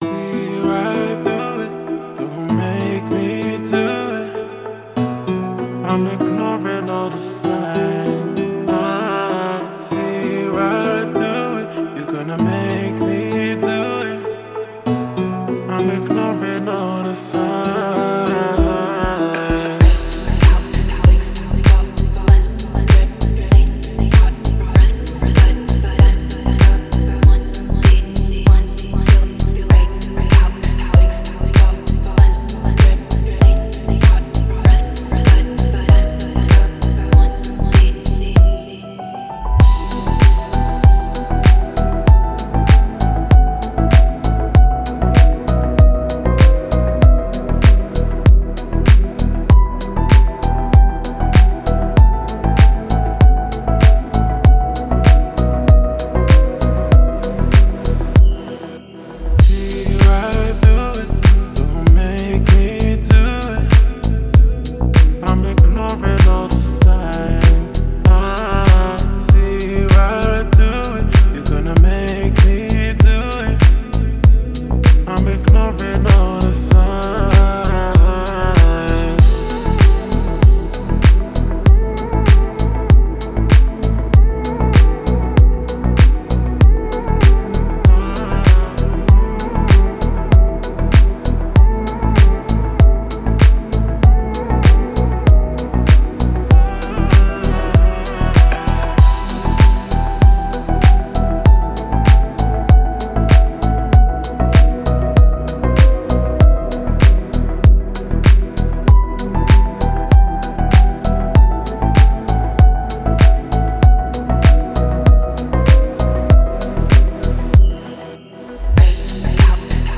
приятная музыка